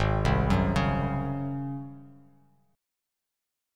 Listen to G#11 strummed